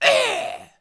monster / barbarian_soldier / dead_2.wav
dead_2.wav